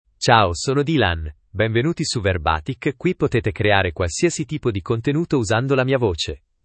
DylanMale Italian AI voice
Dylan is a male AI voice for Italian (Italy).
Voice sample
Listen to Dylan's male Italian voice.
Male
Dylan delivers clear pronunciation with authentic Italy Italian intonation, making your content sound professionally produced.